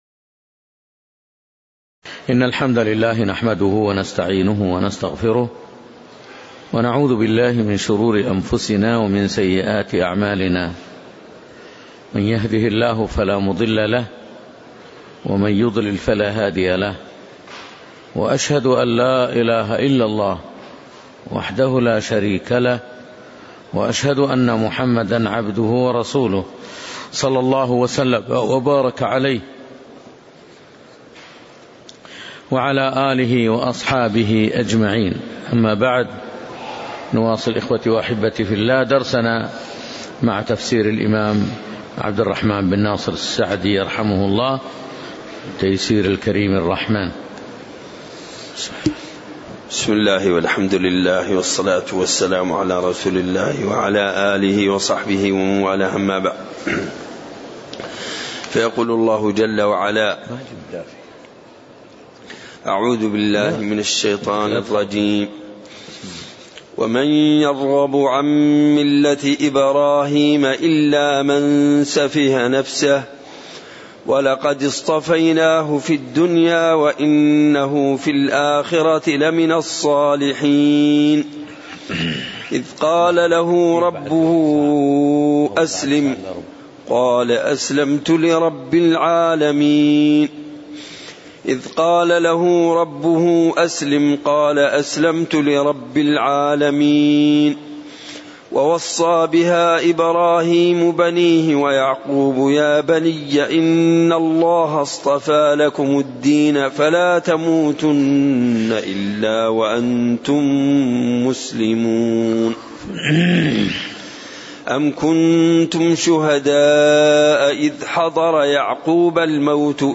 تاريخ النشر ١٤ جمادى الأولى ١٤٣٨ هـ المكان: المسجد النبوي الشيخ